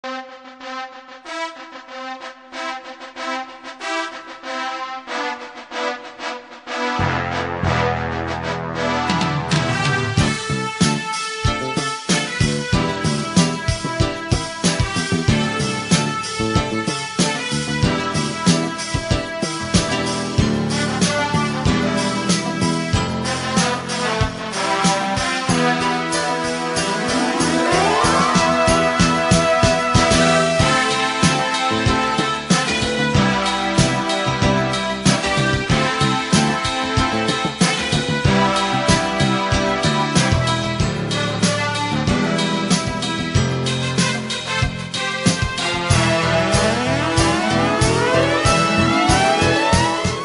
Kategorien: Filmmusik